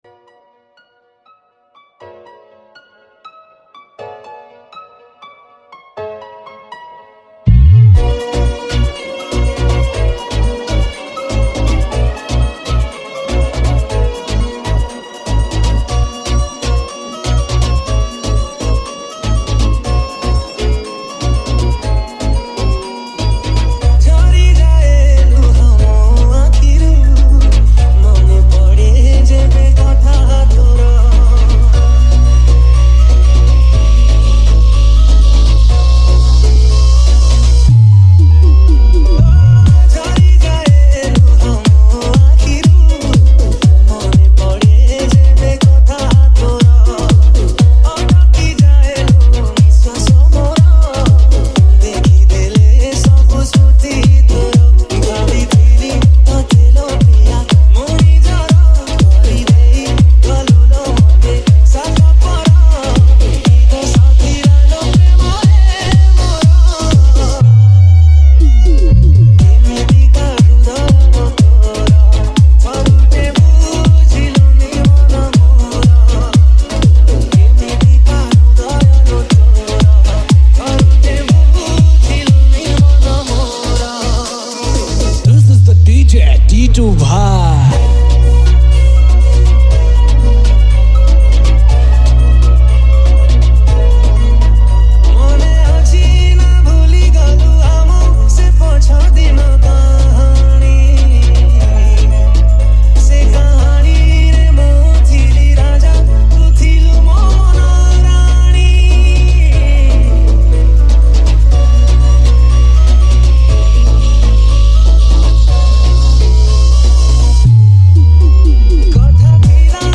Category:  New Odia Dj Song 2023